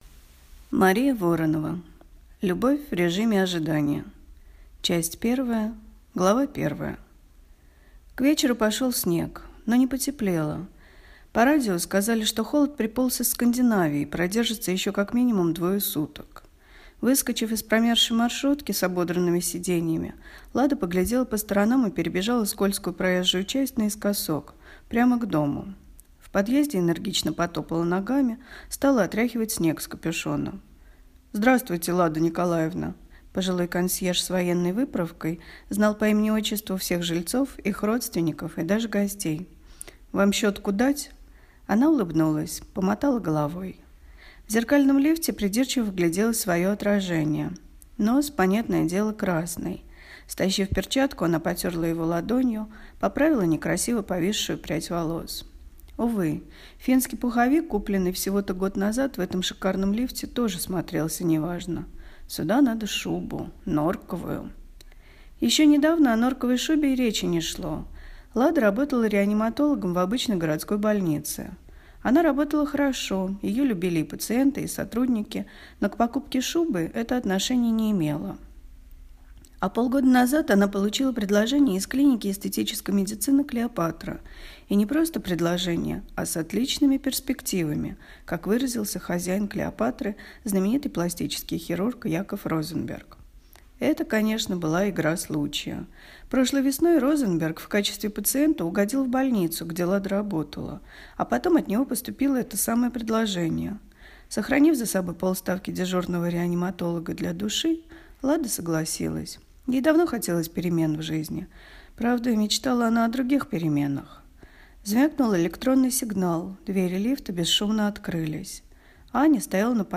Аудиокнига Любовь в режиме ожидания | Библиотека аудиокниг
Прослушать и бесплатно скачать фрагмент аудиокниги